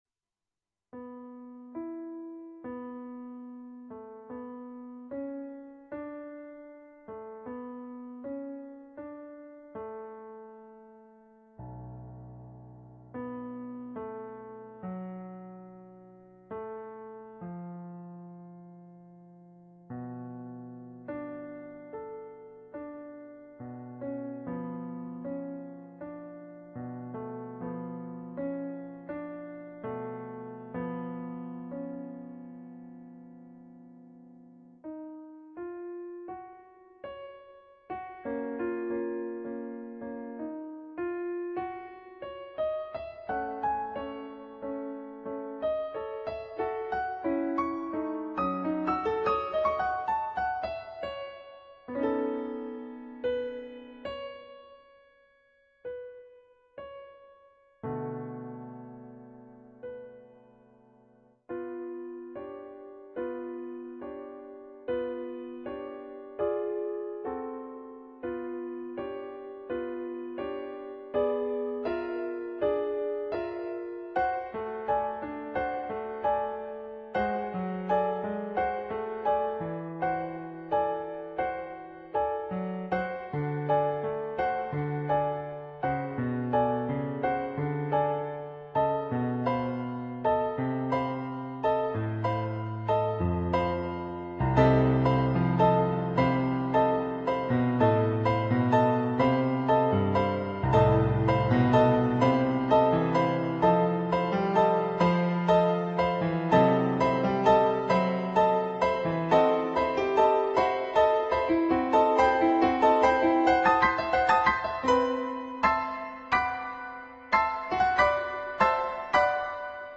Moderato triste (2'30")
on Yamaha digital pianos.